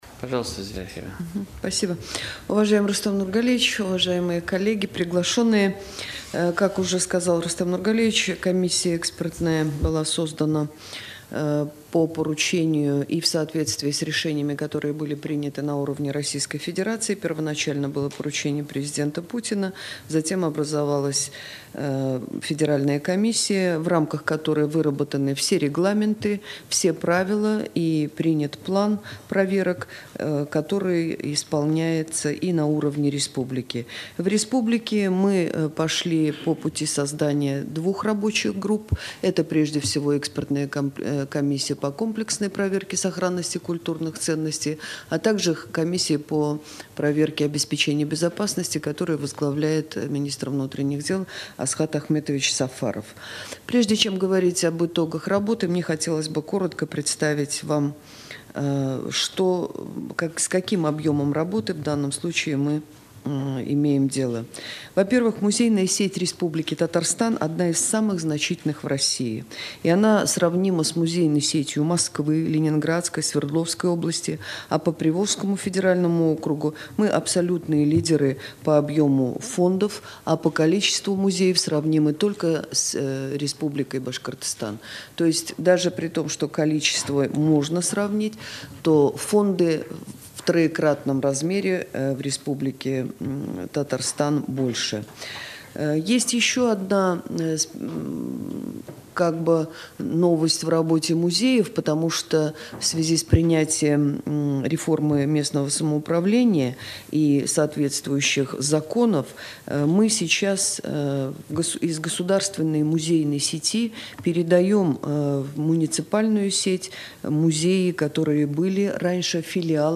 Аудиорепортаж